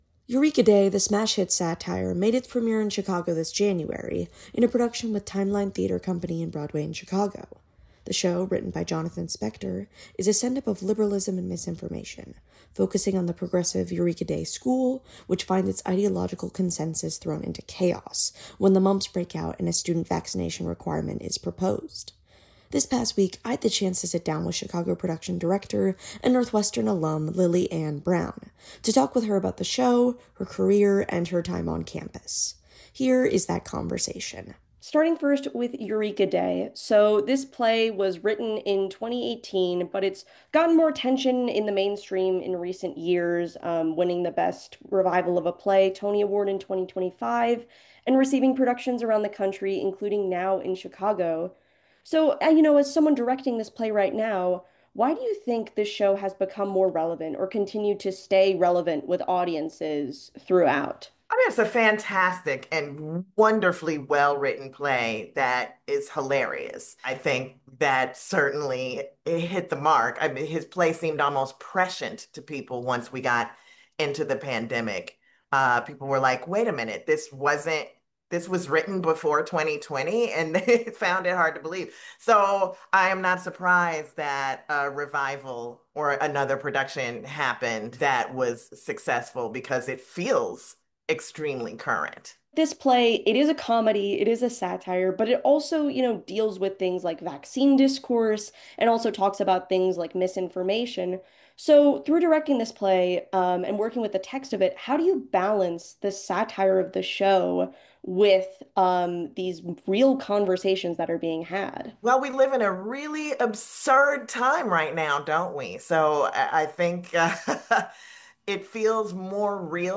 Here is that conversation.